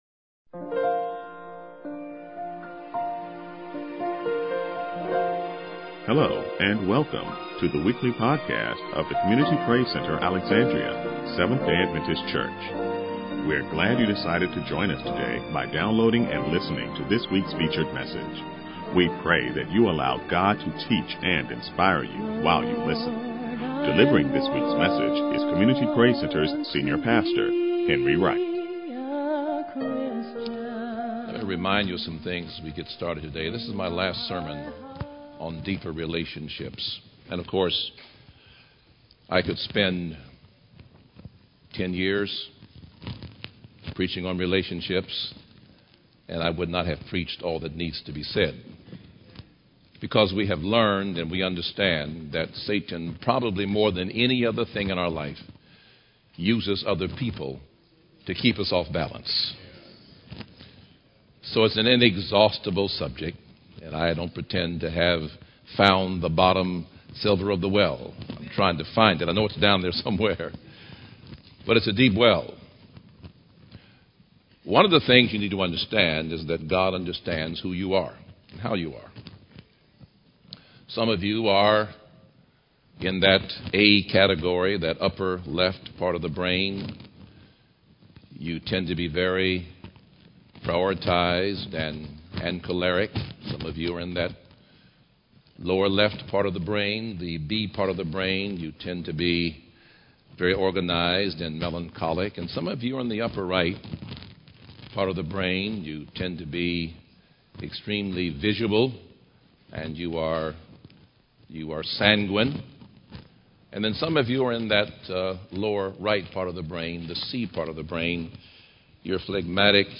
Pilgrim Road SDA Church - Internet Sermons